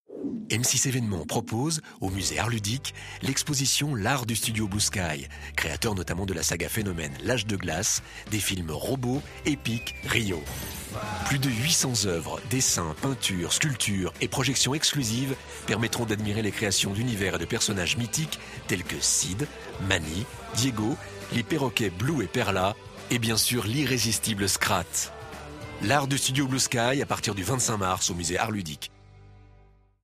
Bande annonce audio de l’exposition Arts Ludiques sur M6, voix-off